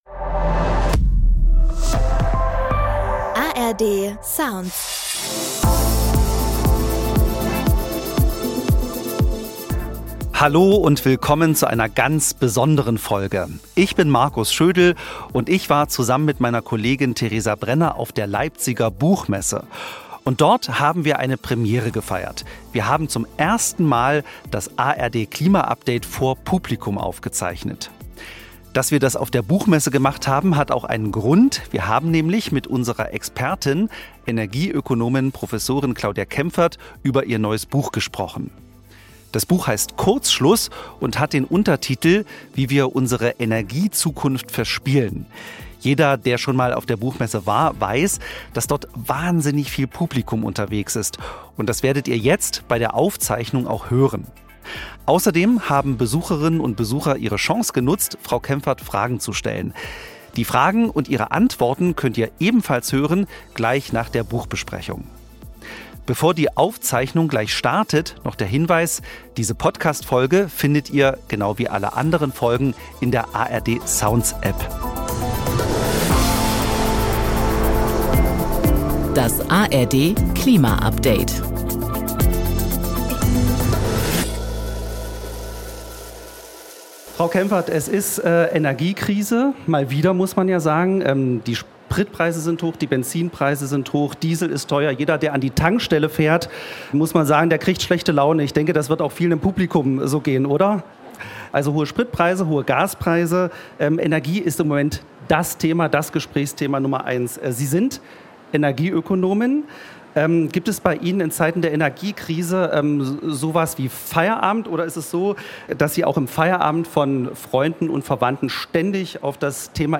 Wie sich Energiekrisen verhindern lassen (live von der Buchmesse) ~ ARD Klima Update Podcast
Beschreibung vor 6 Tagen Auf der Leipziger Buchmesse haben wir mit Energie-Ökonomin Claudia Kemfert über ihr neues Buch „Kurzschluss“ gesprochen. Kemfert erklärt, warum wir derzeit die bisher größte fossile Energiekrise erleben.
Am Ende der Diskussion konnten die Besucherinnen und Besucher der Buchmesse Claudia Kemfert Fragen stellen.